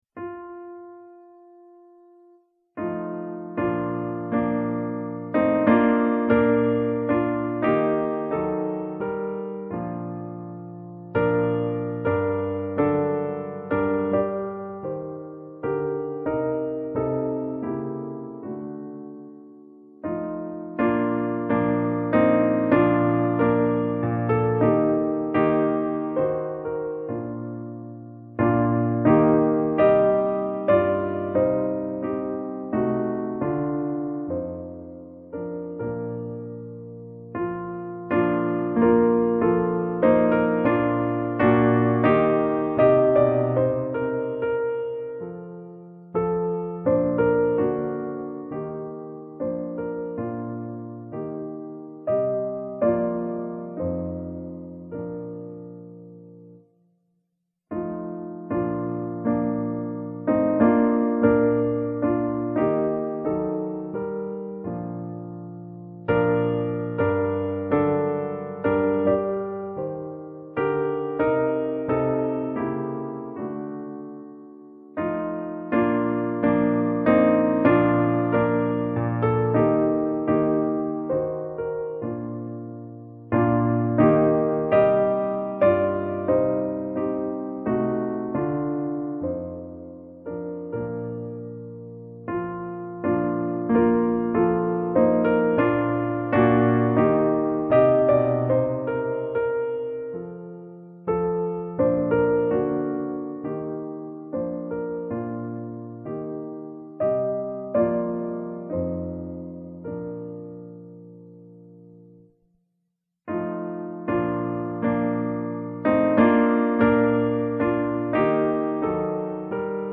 154-EF-Wiegenlied.mp3